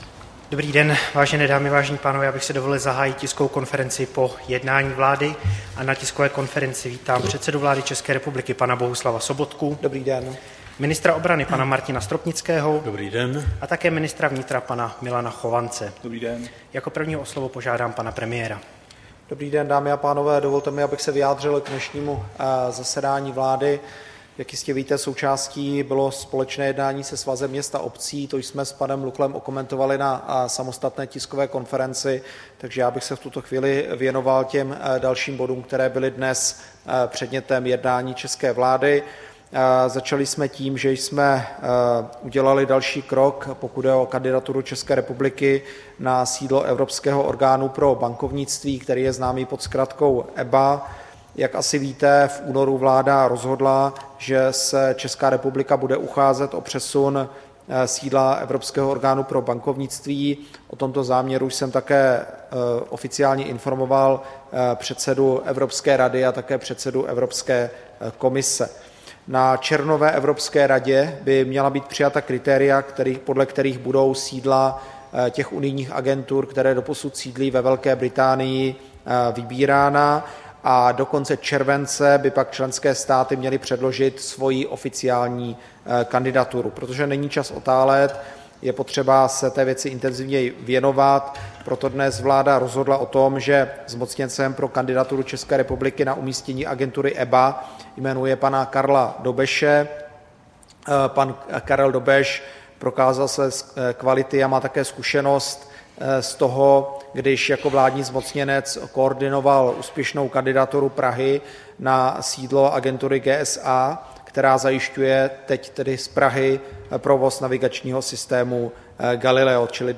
Tisková konference po jednání vlády, 5. června 2017